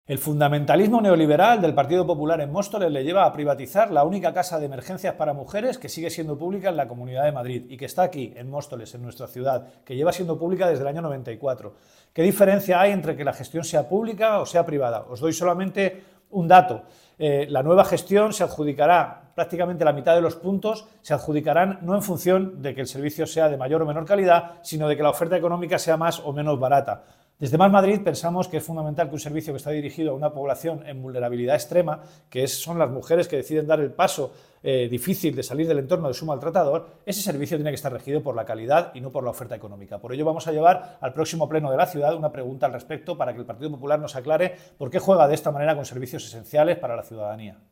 Emilio Delgado. Declaraciones Centro de Emergencias
declaraciones-emilio-delgado-centro-emergencias.mp3